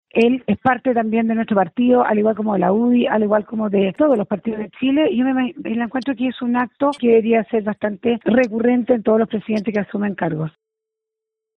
Al respecto, la diputada Ximena Ossandón sostuvo que la decisión de José Antonio Kast de poner fin a su militancia es un acto simple, pero que debería ser replicado por todos los futuros mandatarios antes de asumir la presidencia.